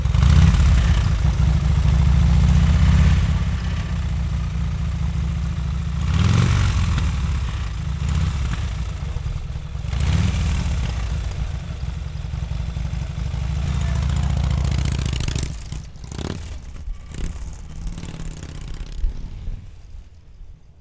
harley.wav